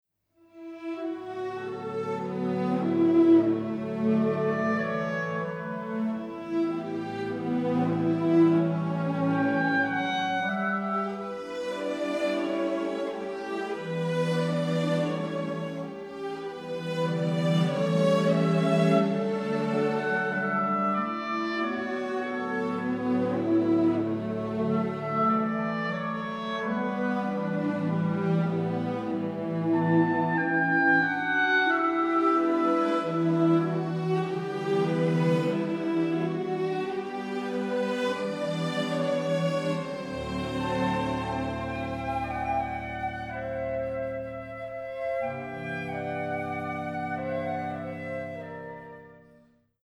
Recorded on the OSE orchestra stage in September 2018